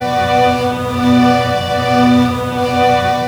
Index of /90_sSampleCDs/USB Soundscan vol.02 - Underground Hip Hop [AKAI] 1CD/Partition E/05-STRINGS